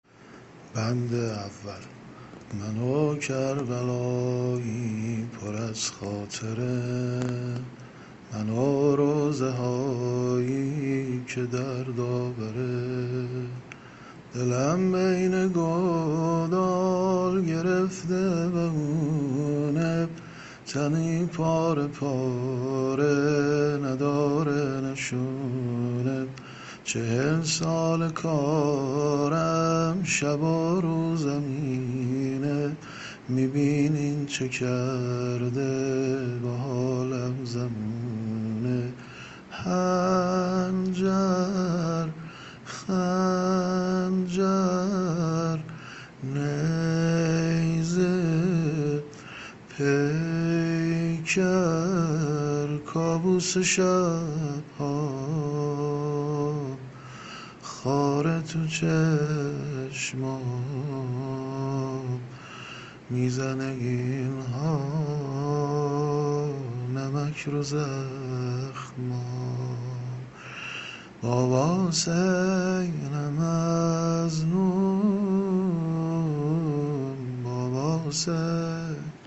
شعر زمزمه و واحد شهادت امام سجاد (ع) -(منو کربلایی پر از خاطره)